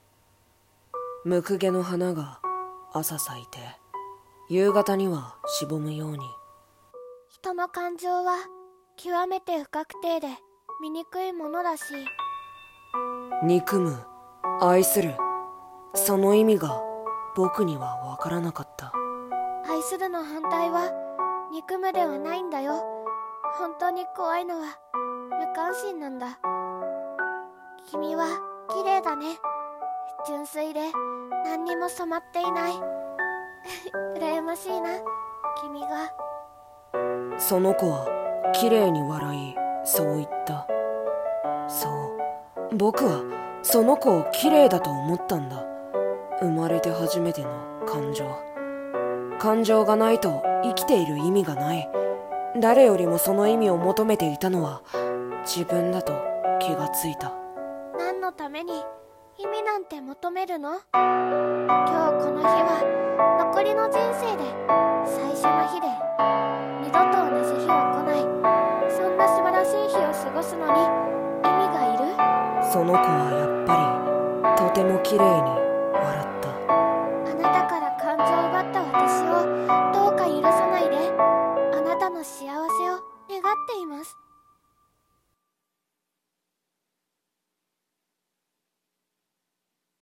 恋蛍、泪に沈む。【掛け合い台本】 演者